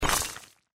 block_crash.mp3